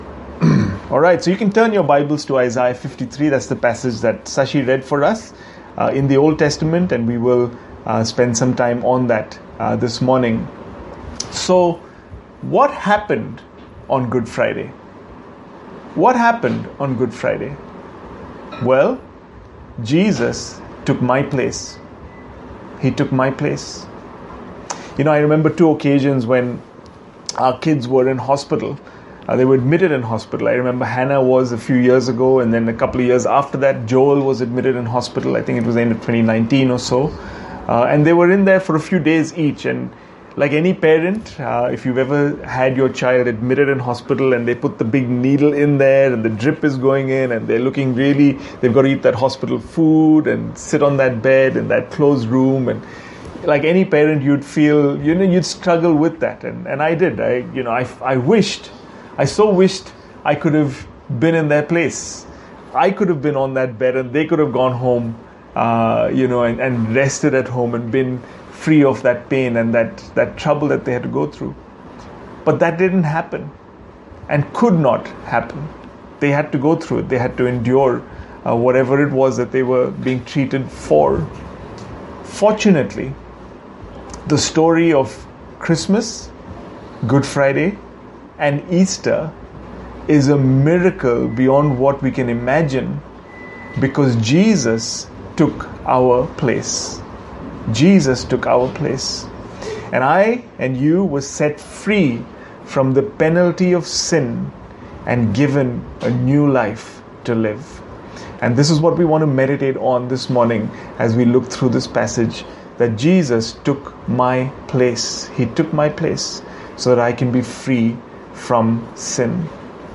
Good Friday Sermon Topic: He took my place
good-friday-sermon-topic-he-took-my-place.mp3